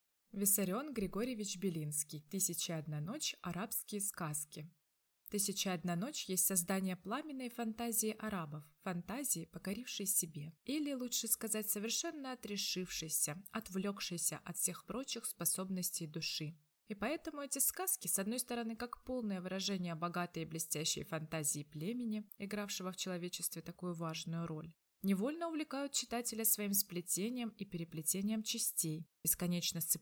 Аудиокнига Тысяча и одна ночь, арабские сказки | Библиотека аудиокниг